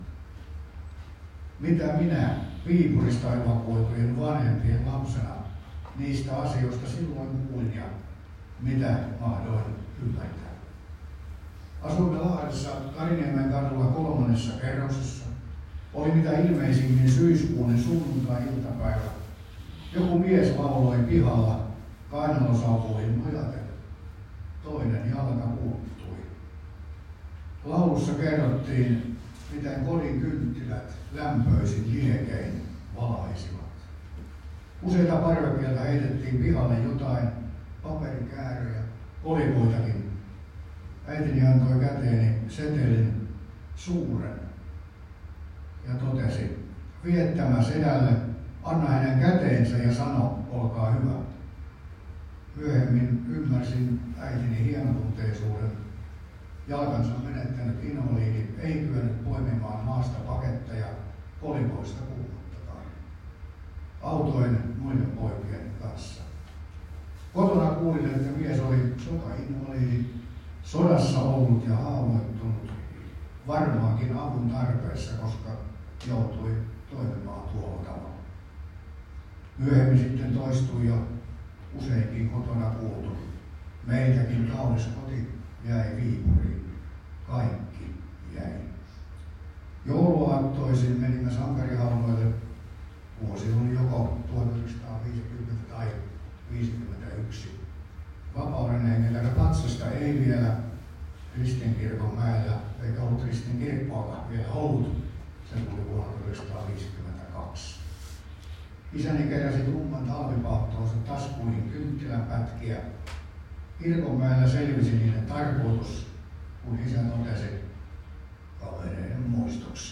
Isänmaallinen ErP 6:n muistojuhla Heinolassa 27.12.2024 - Käkisalmi-säätiö
Käkisalmi-museolla ja Jyränkölässä järjestettyyn juhlaan osallistui noin kuusikymmentä perinteen vaalijaa.